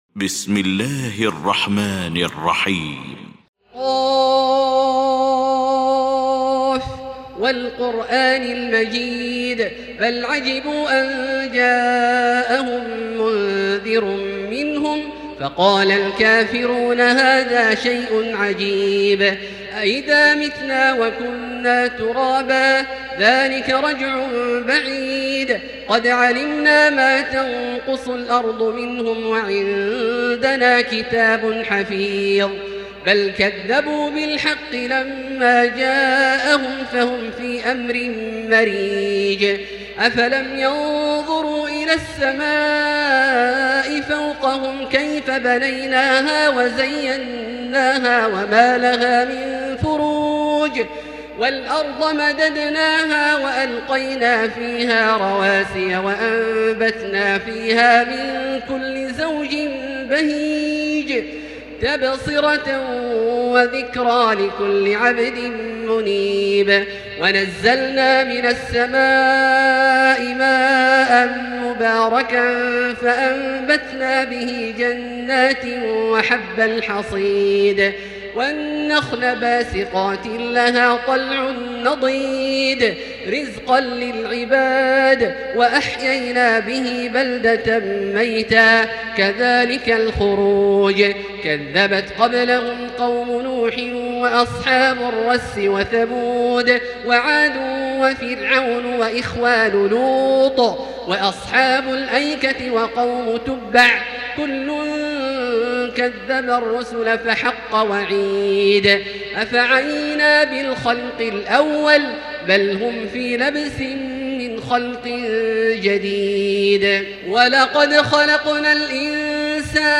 المكان: المسجد الحرام الشيخ: فضيلة الشيخ عبدالله الجهني فضيلة الشيخ عبدالله الجهني ق